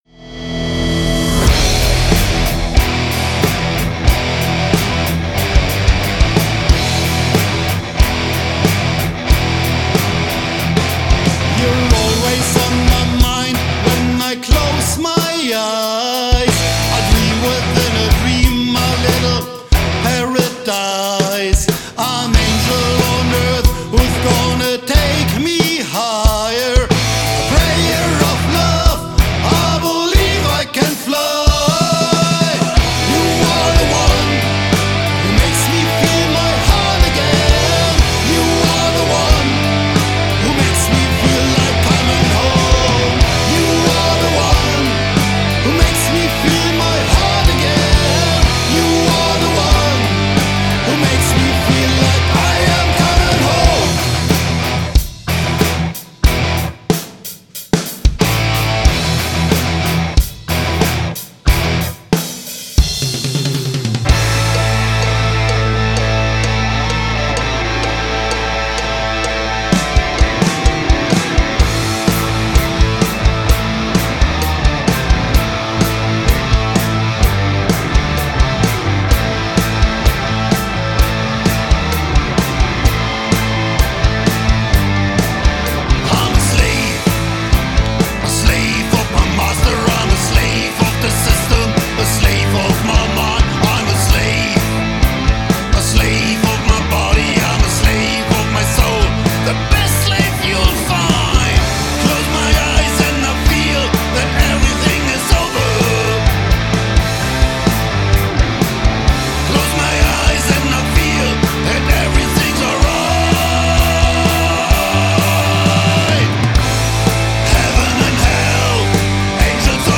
OLDS COOL PROG'N'ROLL